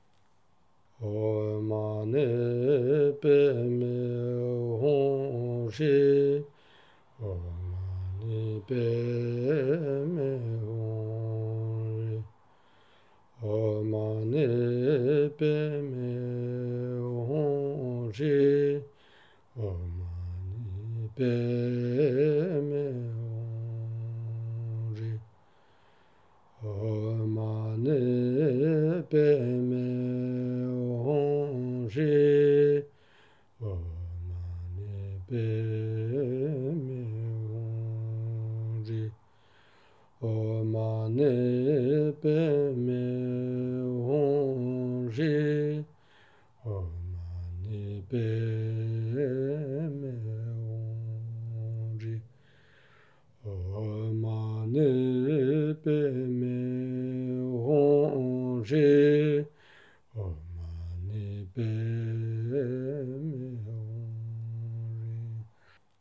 Prières de base
Mantra de Tchenrezi - Om mani padme hung